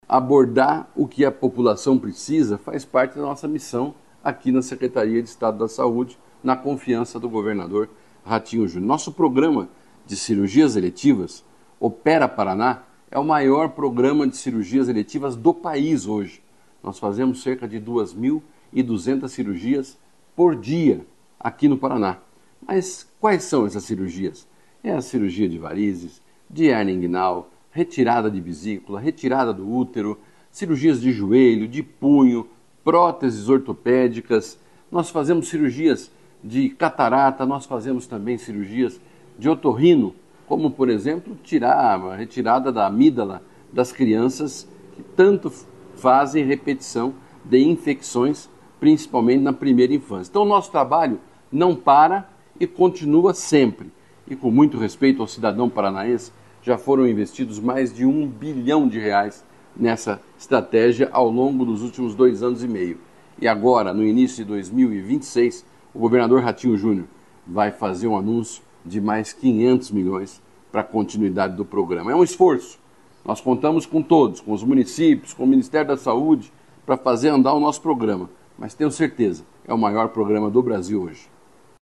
Sonora do secretário da Saúde, Beto Preto, sobre o número de cirurgias eletivas no Estado